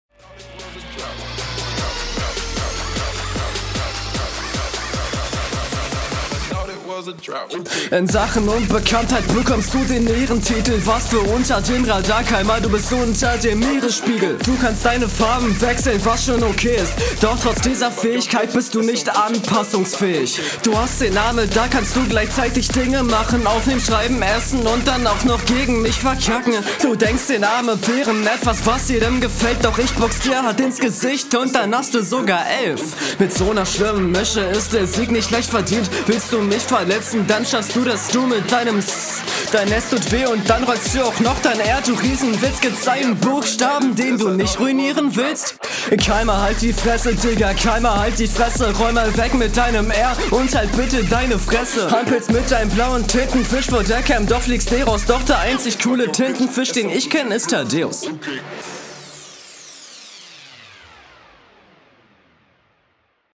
Fresher Beat!
Hier ist der Beat zu leise und deine Stimme zu laut.